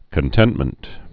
(kən-tĕntmənt)